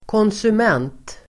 Ladda ner uttalet
konsument substantiv, consumer Uttal: [kånsum'en:t] Böjningar: konsumenten, konsumenter Synonymer: användare, förbrukare Definition: person som köper varor (el. tjänster), köpare Relaterade ord: producent (antonym) (producer)